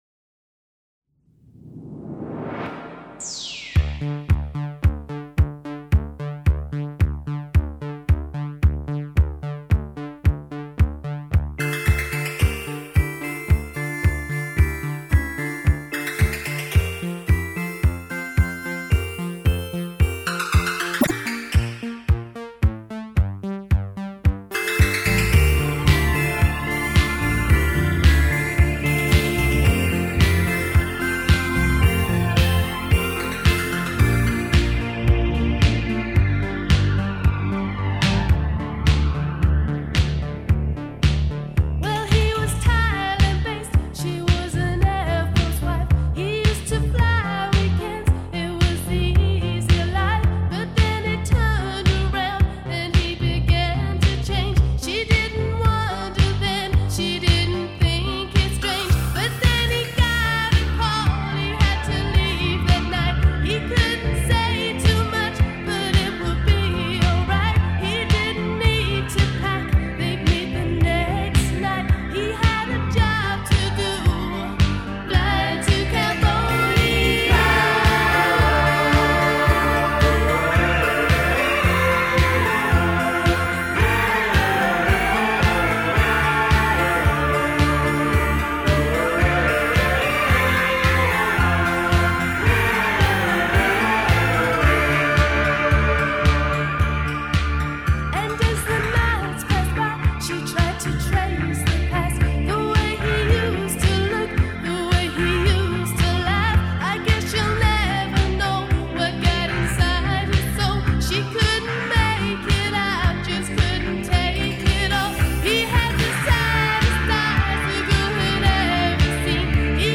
Género: Pop.